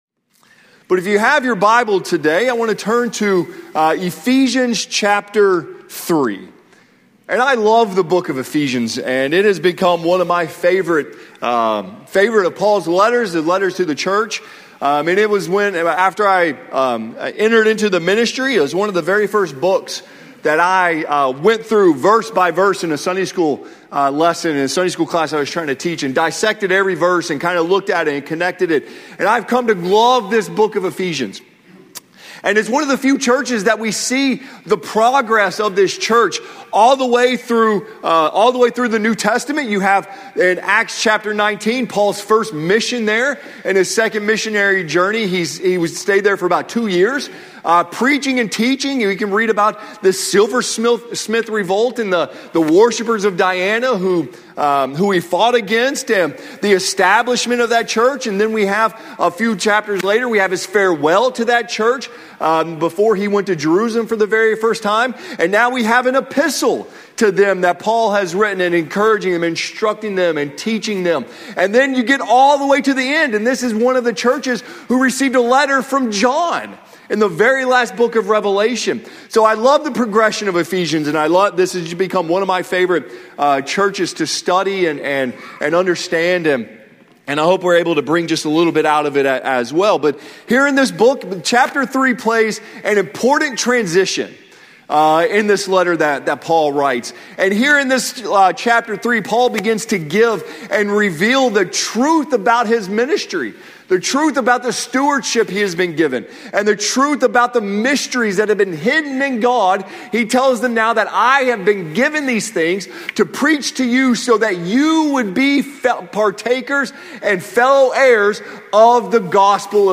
Friday Morning Devotional from the 2003 session of the Old Union Ministers School.